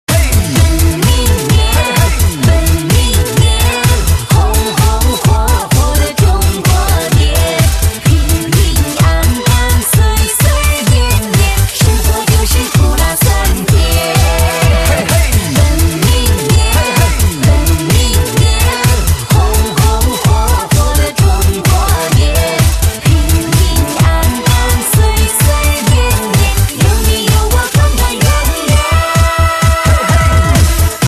DJ铃声, M4R铃声, MP3铃声 78 首发日期：2018-05-15 11:15 星期二